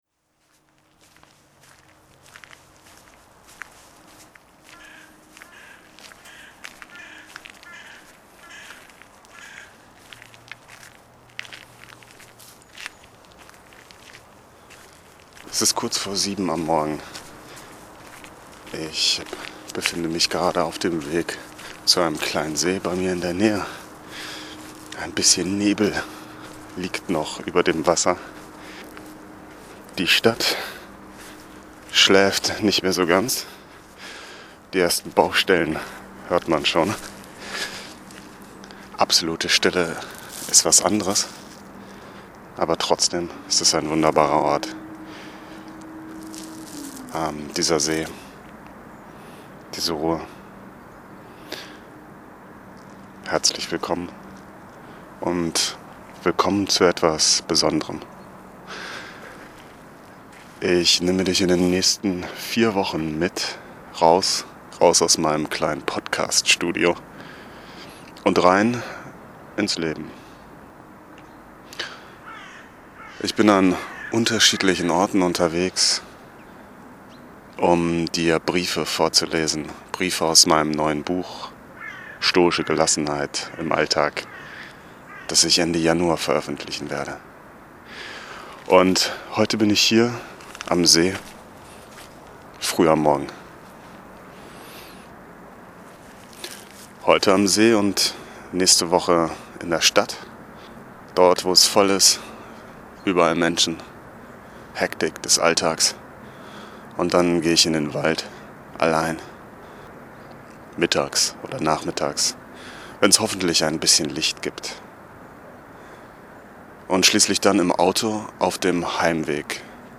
Der Morgen am See – Drei Säulen für unerschütterliche Stärke #193 ~ Stoizismus heute Podcast
Das ist Folge 1 von 4 Folgen, die ich an besonderen Orten aufnehme.